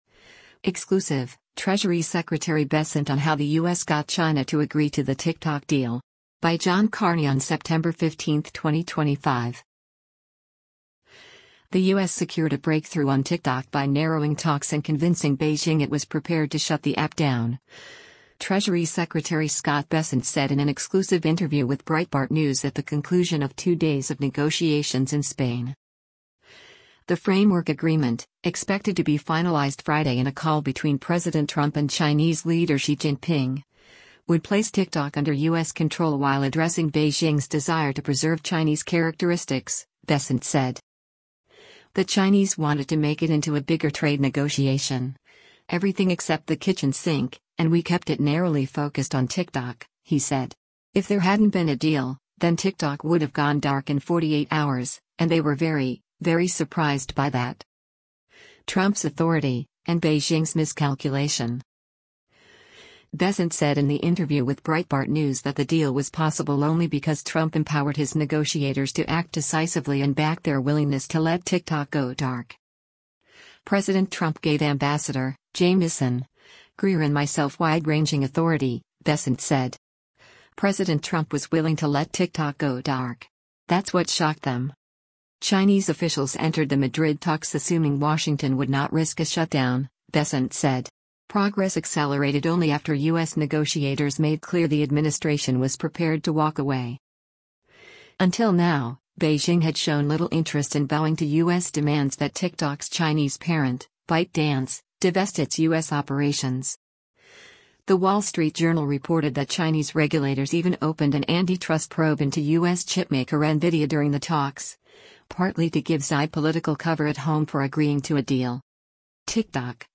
The U.S. secured a breakthrough on TikTok by narrowing talks and convincing Beijing it was prepared to shut the app down, Treasury Secretary Scott Bessent said in an exclusive interview with Breitbart News at the conclusion of two days of negotiations in Spain.